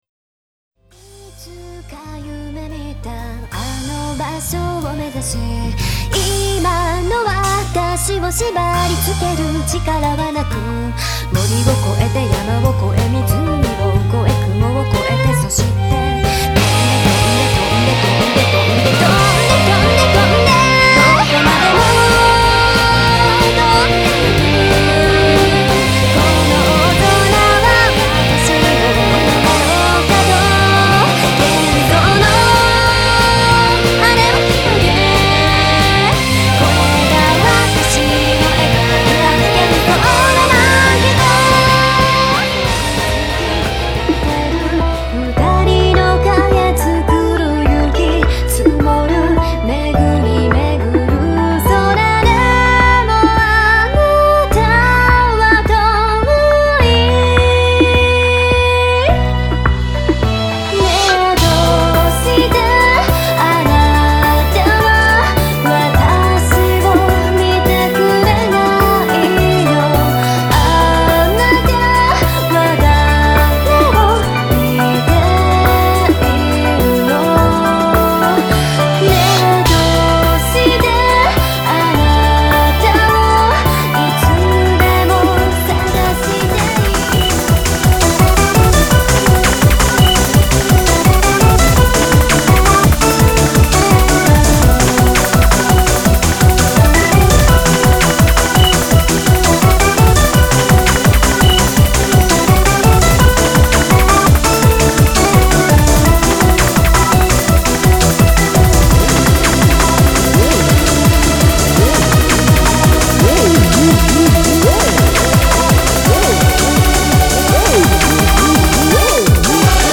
ボーカル、インスト、共々に様々なジャンルを詰め込んだよりどりみどりの一枚。
全曲クロスフェード！